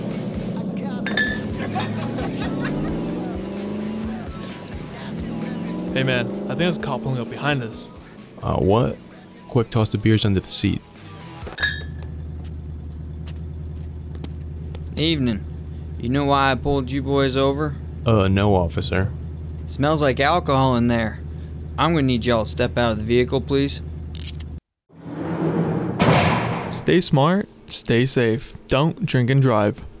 NAVAL AIR STATION SIGONELLA, Italy (Oct. 3, 2024) Radio spot raises awareness about drunk driving.